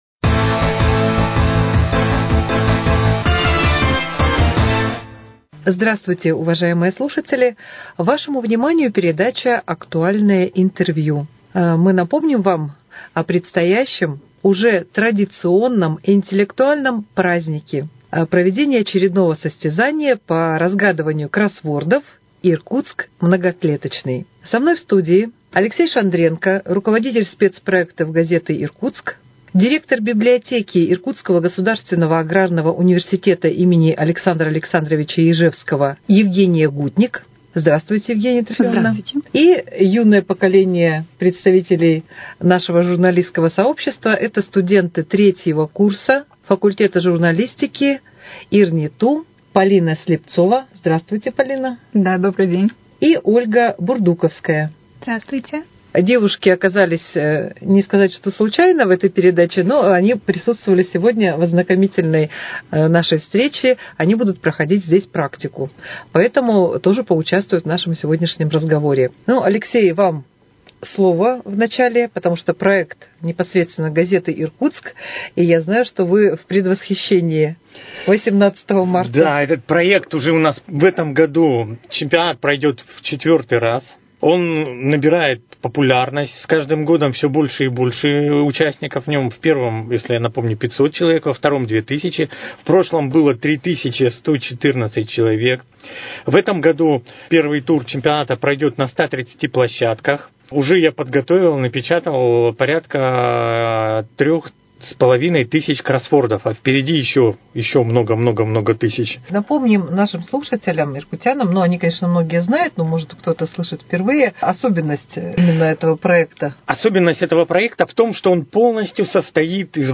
Актуальное интервью: О подготовке к чемпионату по разгадыванию кроссвордов «Иркутск многоклеточный»